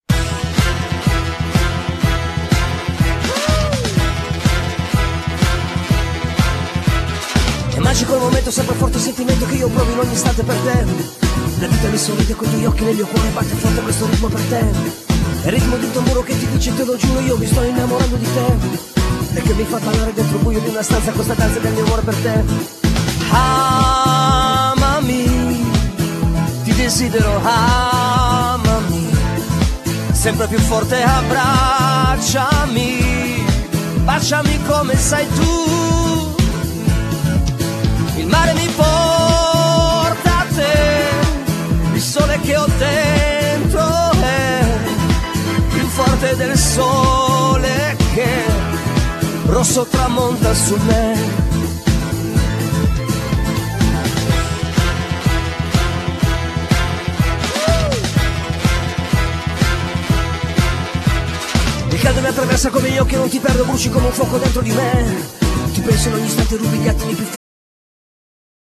Genere : Pop FOLK